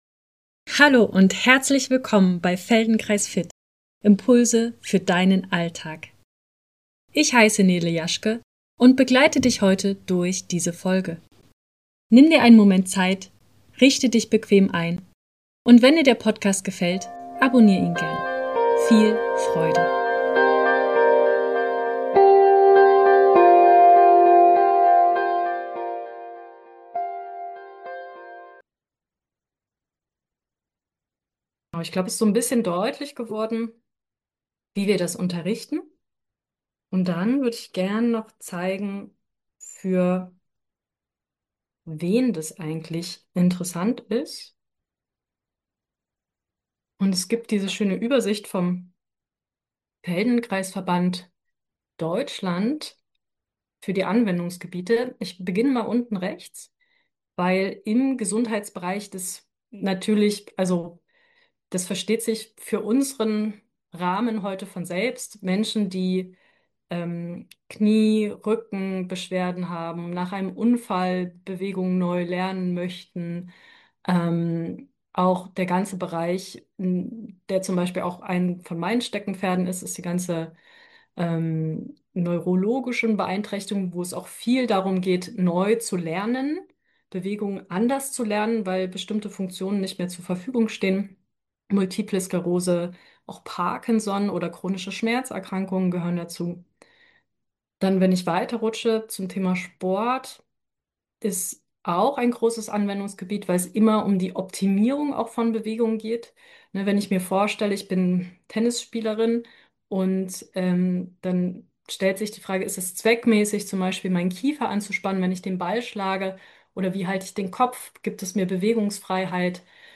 Ausschnitt Symposiums-Vortrag "Feldenkrais und Resilienz"